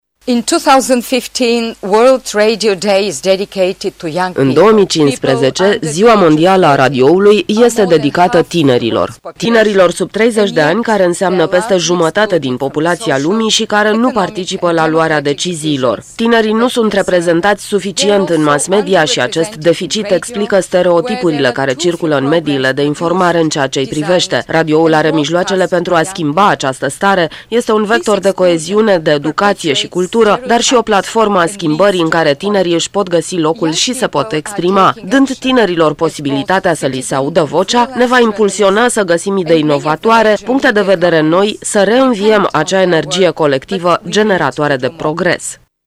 stiri-13-feb-Ziua-Radio.mp3